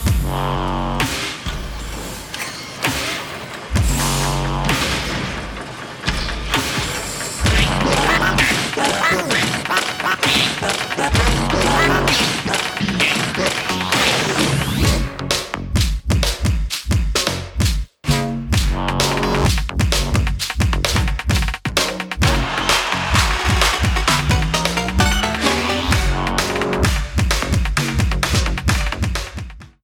The music from the intro